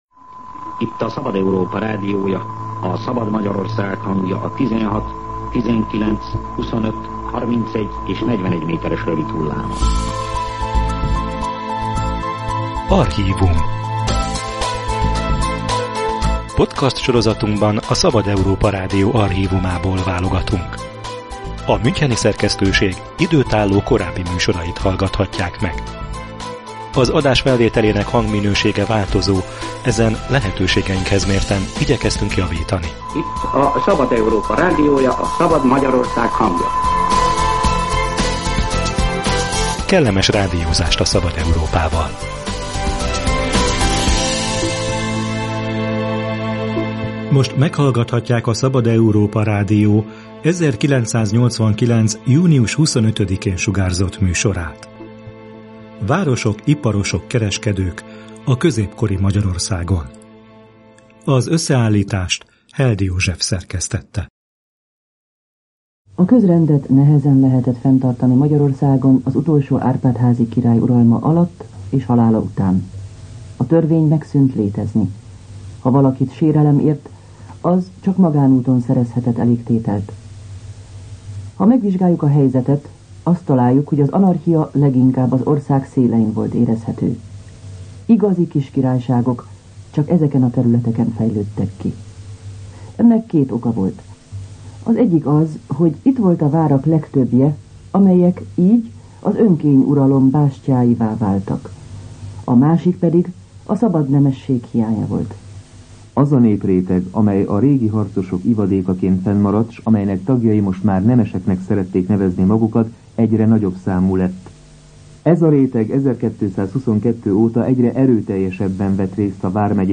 Ezzel az időszakkal foglalkozott a Szabad Európa Rádió 1989-es történelmi műsora.